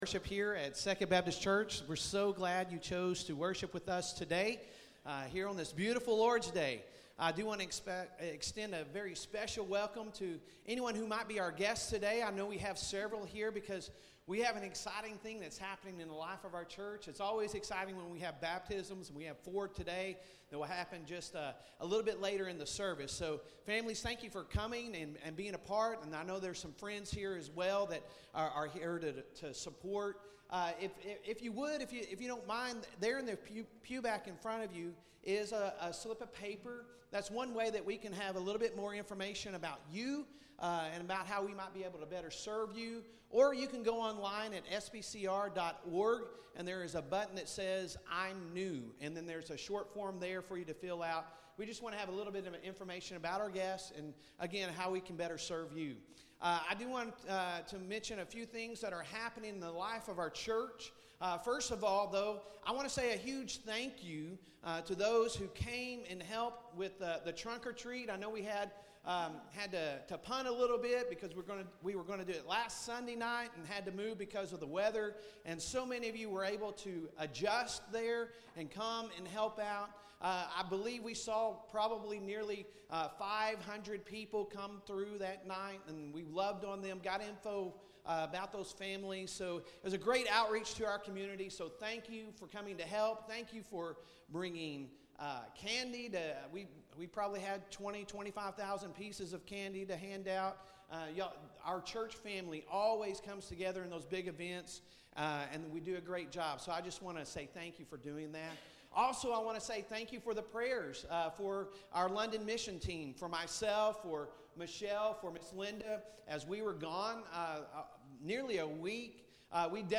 Sunday Sermon November 5, 2023